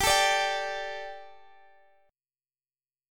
Listen to Gsus2 strummed